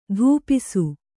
♪ dhūpisu